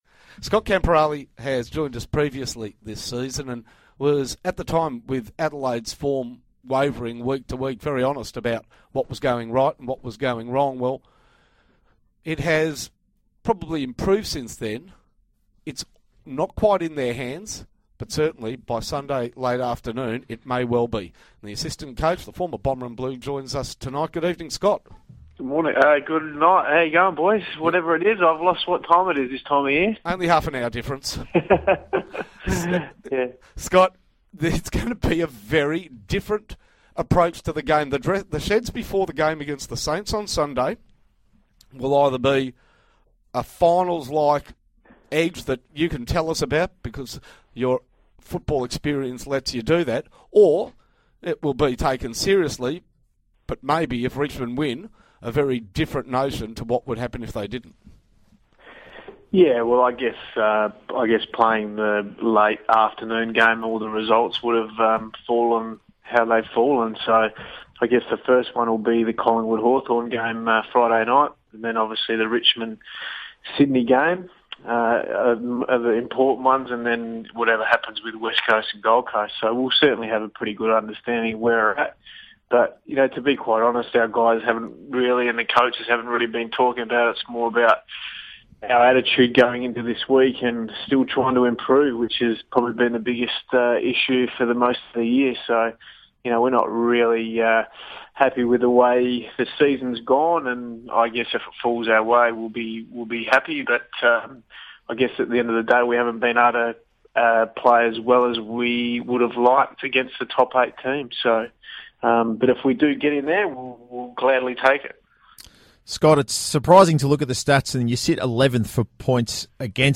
Crows midfield coach Scott Camporeale spoke on Melbourne radio station SEN as Adelaide prepares for its do-or-die clash with St Kilda on Sunday